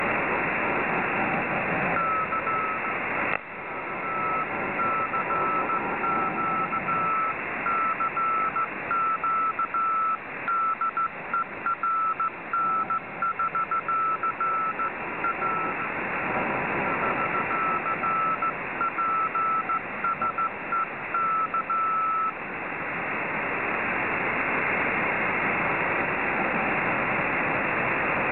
Вот так себя слышу на Тамбовском который на карте №22